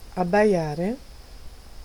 Ääntäminen
IPA : /beɪ/